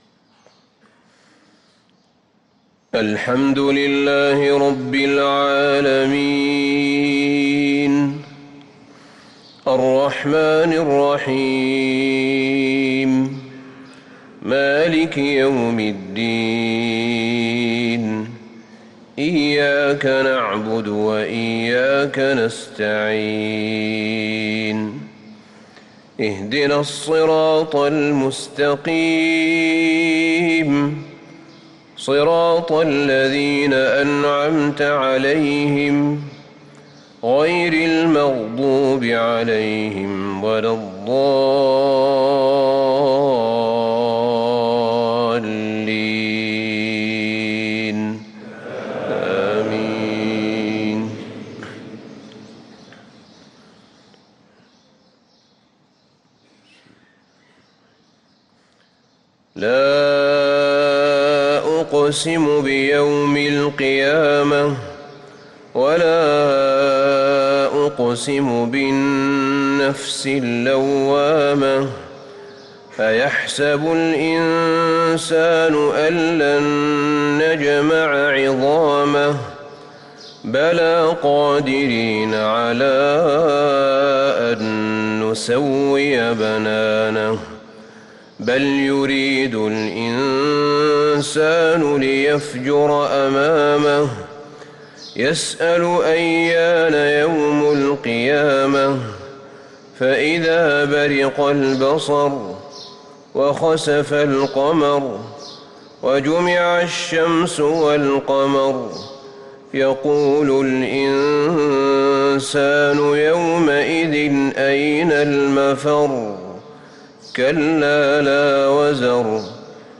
صلاة الفجر للقارئ أحمد بن طالب حميد 4 جمادي الأول 1445 هـ